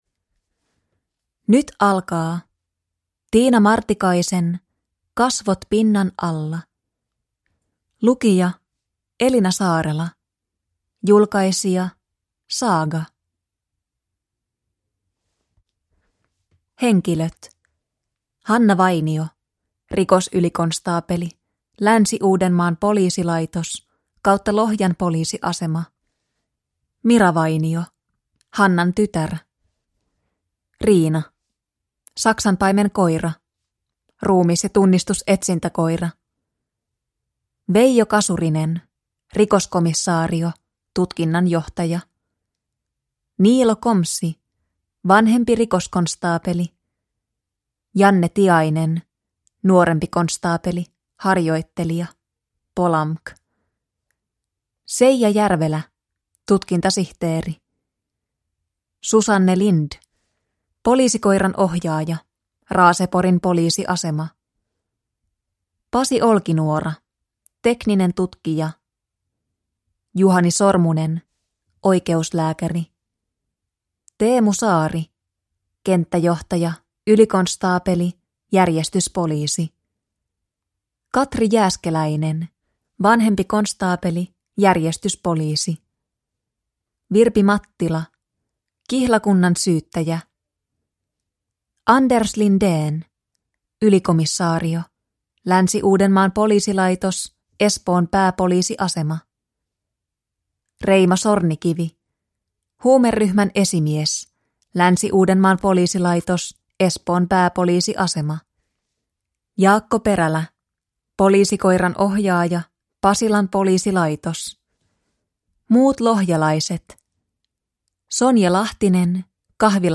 Kasvot pinnan alla / Ljudbok